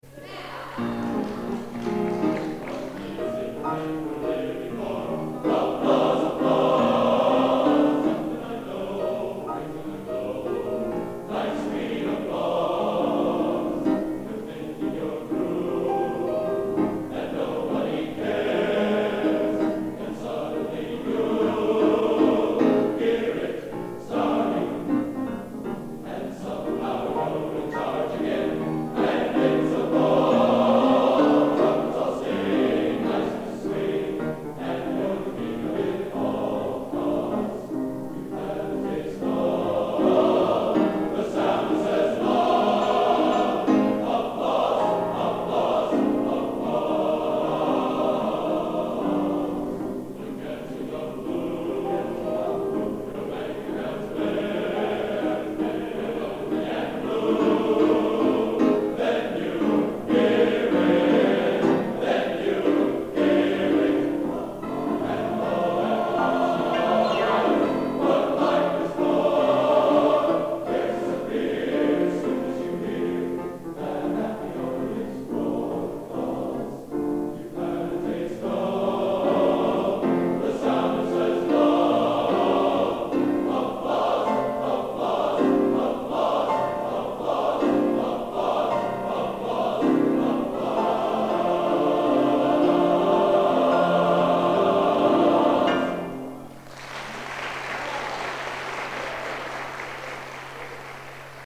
Location: Old Academy of Music, Stockholm, Sweden